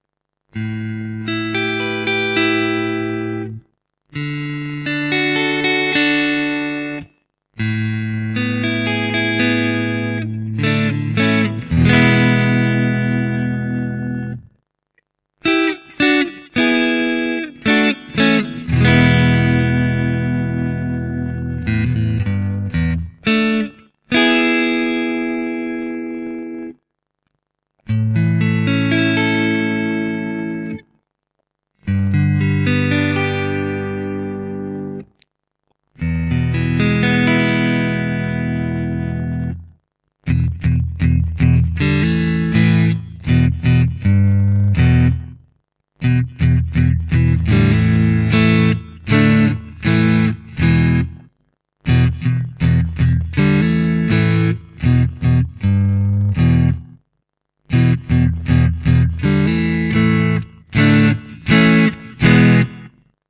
The Aeriads are open sounding vintage pickups.
This gives it more openness and character than vintage tele neck pickups usually have.